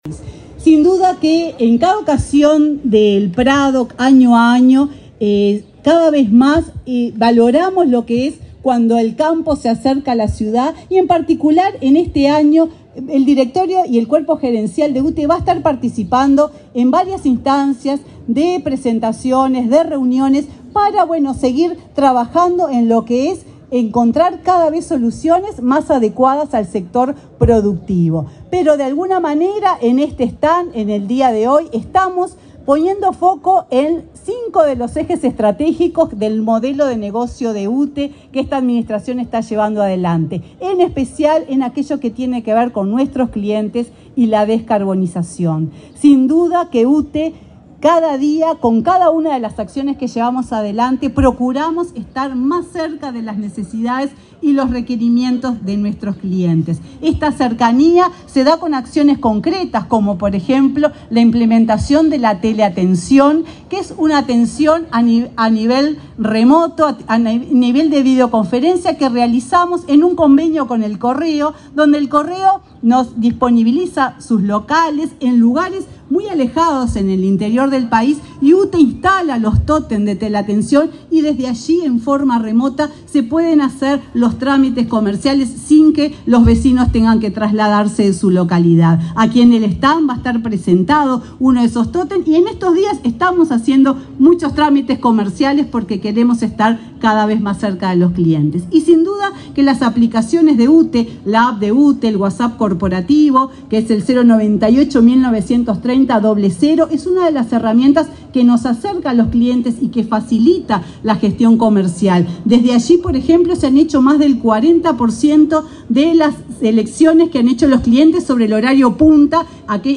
Palabras de autoridades en inauguración de stand de UTE
La presidenta de UTE, Silvia Emaldi, y el ministro de Industria, Omar Paganini, encabezaron la inauguración del stand del ente energético en la Expo